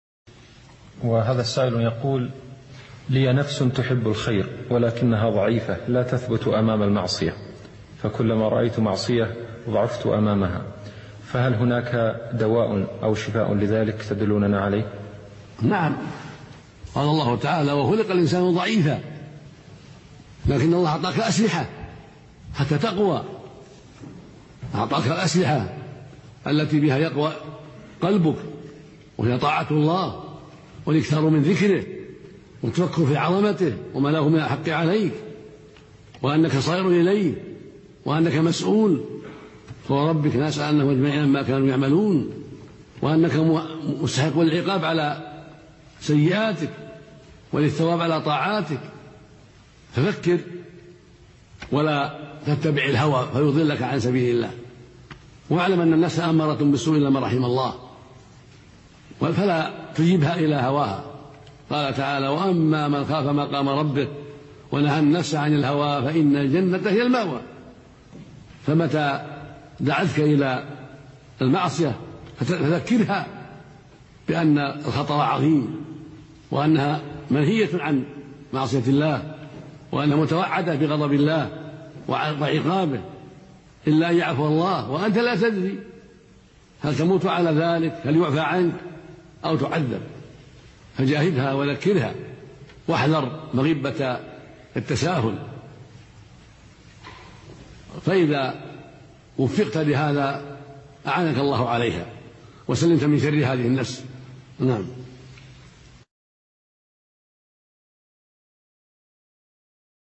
Album: موقع النهج الواضح Length: 1:50 minutes (508.61 KB) Format: MP3 Mono 22kHz 32Kbps (VBR)